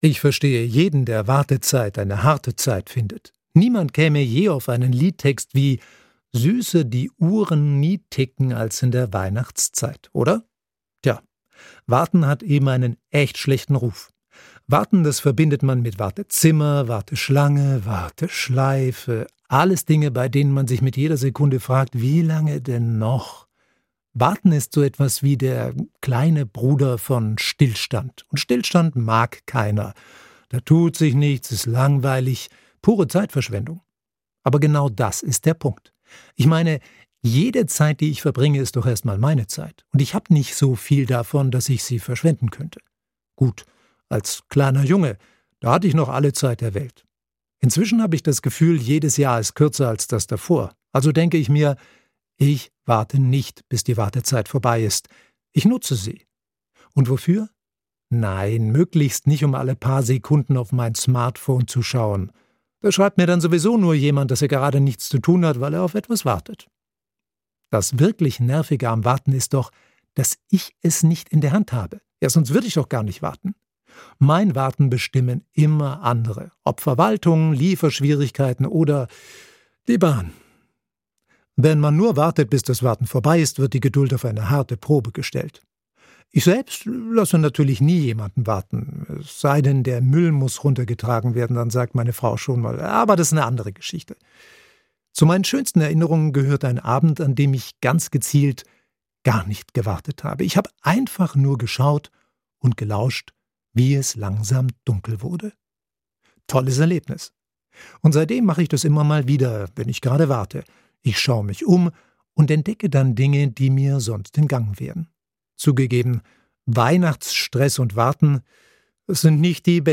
Unser Zwischenruf in SWR1 Sonntagmorgen